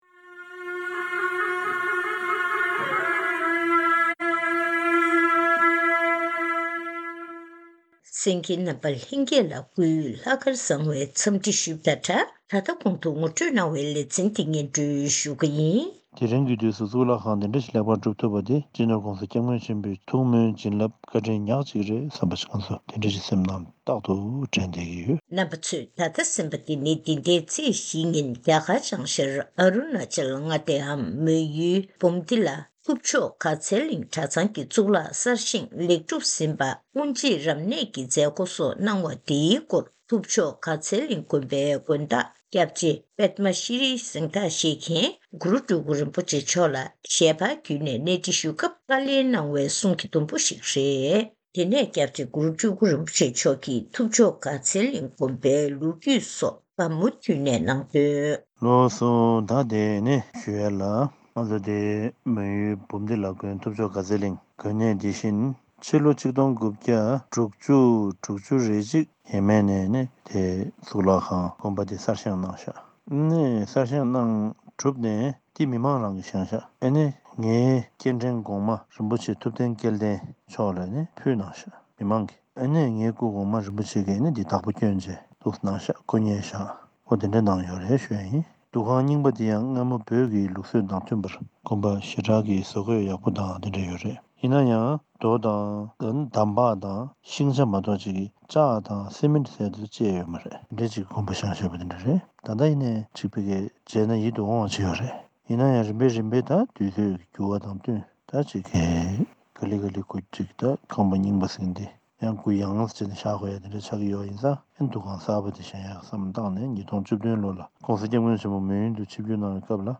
གནས་འདྲི་ཞུས་ནས་གནས་ཚུལ་ཕྱོགས་བསྒྲིགས་ཞུས་པ་ཞིག་གསན་རོགས་གནང་།